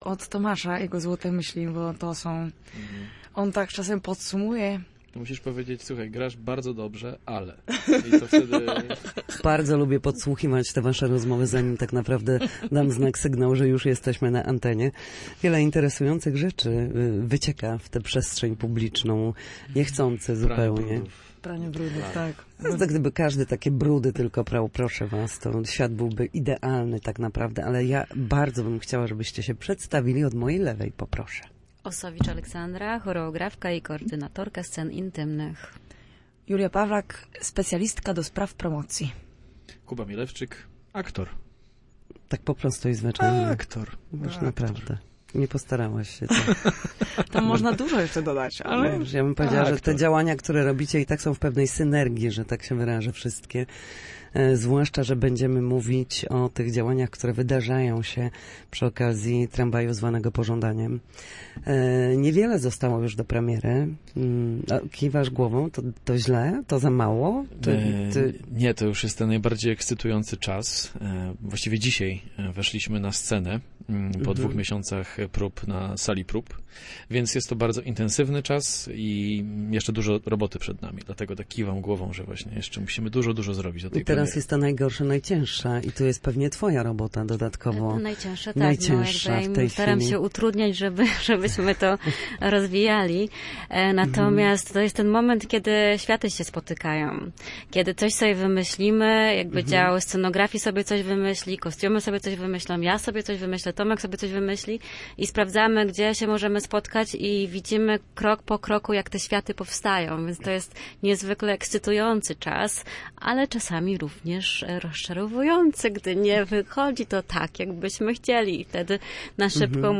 W Studiu Słupsk gośćmi byli